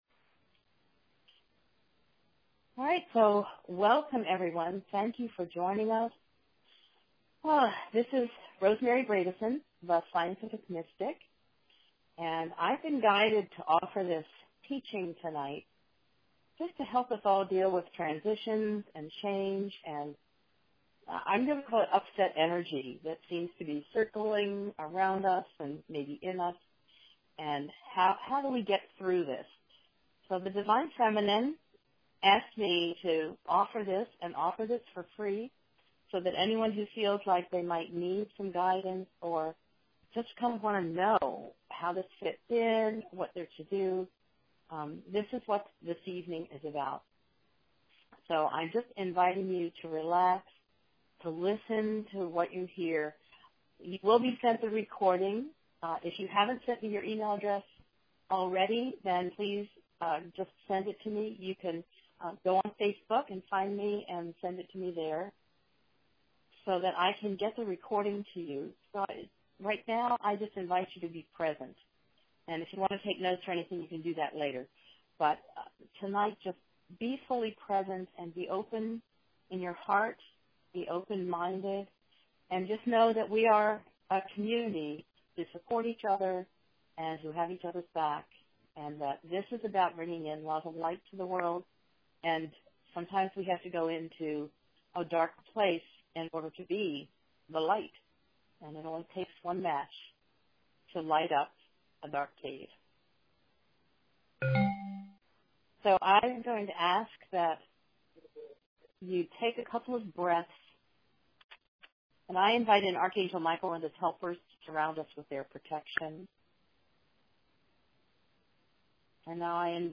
I held a “Guidance Circle” last Thursday evening, following Tuesday’s election of a US president and the changing times that election epitomizes. The Divine Feminine offered a message of guidance and insight.
Many joined us live for this call and have listened to the recording.